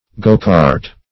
gocart \go"cart`\, go-cart \go"-cart`\n.